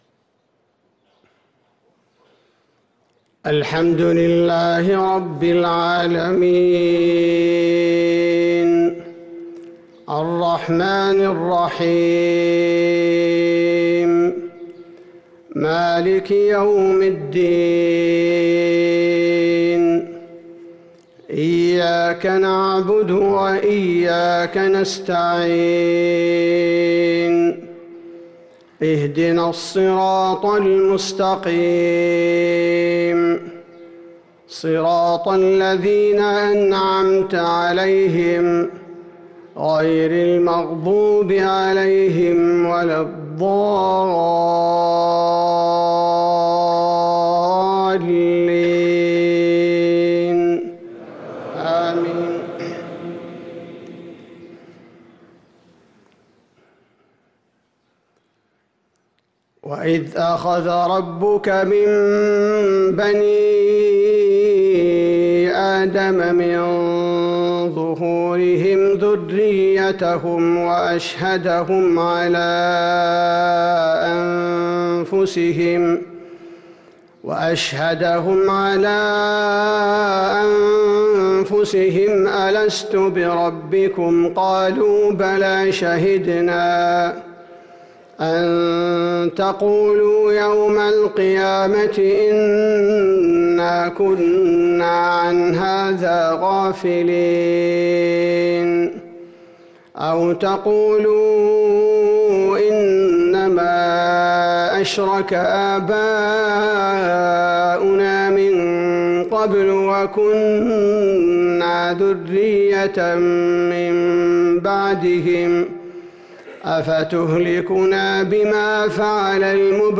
صلاة الفجر للقارئ عبدالباري الثبيتي 5 جمادي الآخر 1445 هـ
تِلَاوَات الْحَرَمَيْن .